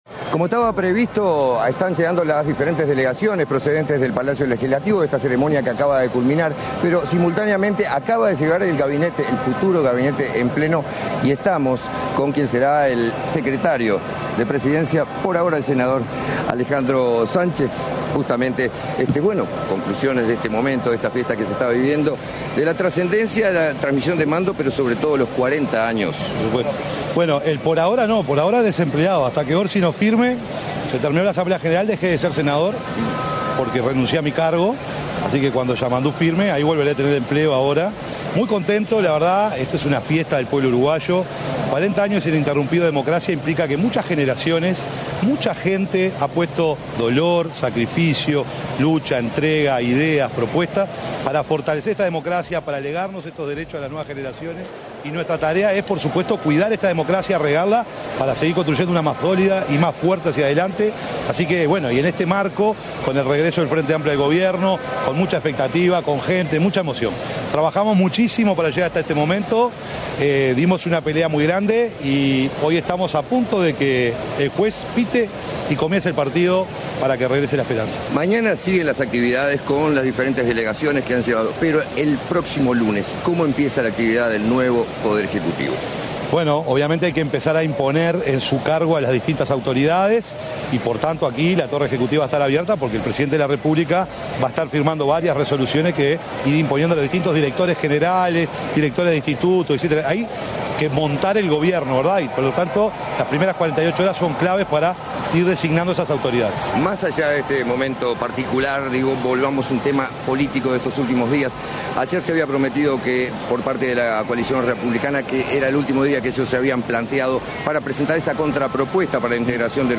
Palabras del secretario de Presidencia designado, Alejandro Sánchez
Palabras del secretario de Presidencia designado, Alejandro Sánchez 01/03/2025 Compartir Facebook X Copiar enlace WhatsApp LinkedIn Este sábado 1.° de marzo, en oportunidad del traspaso de mando presidencial en Uruguay, se expresó el secretario de Presidencia designado, Alejandro Sánchez.